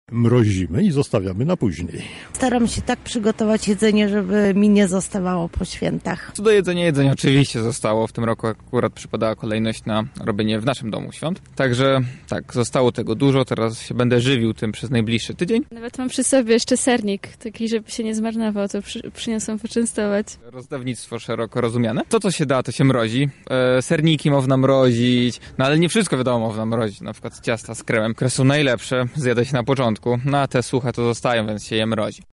SONDA: Sprzątanie z wielkanocnych stołów
Sprawdziliśmy, co z resztkami jedzenia zamierzają zrobić mieszkańcy Lublina:
SONDA